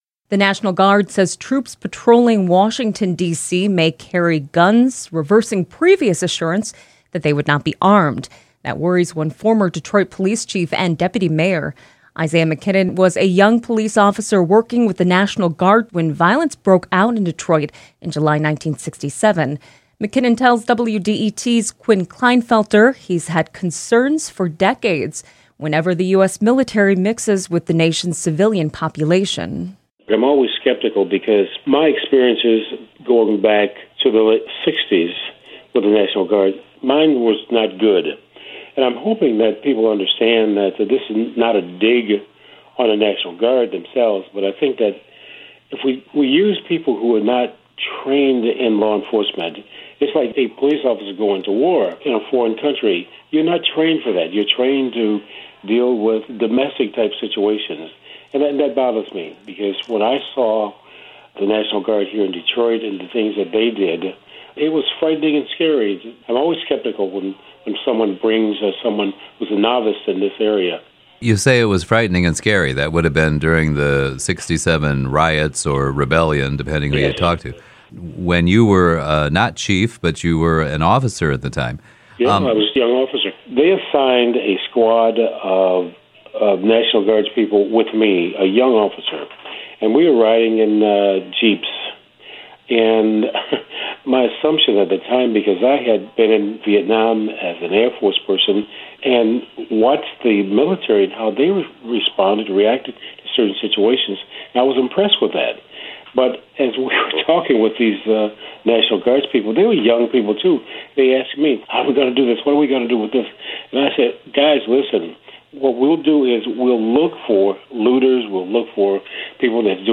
The following interview has been edited for clarity and length